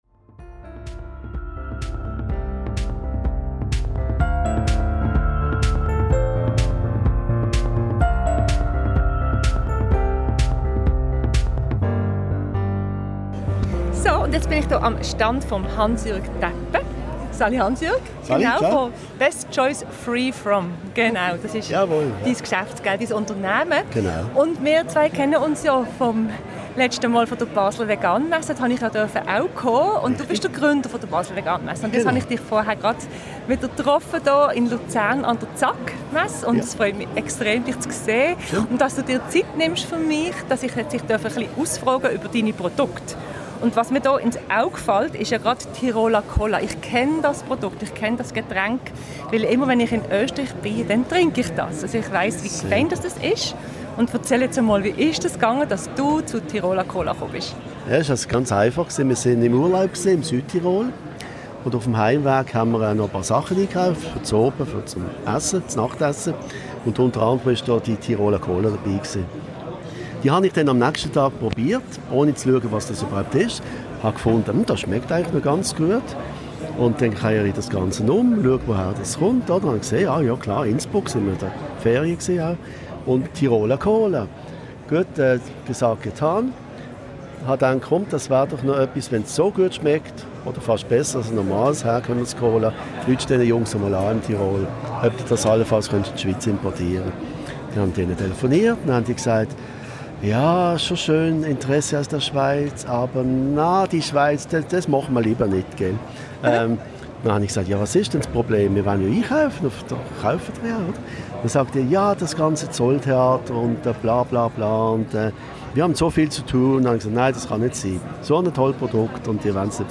Diese Folge ist in Schweizerdeutsch.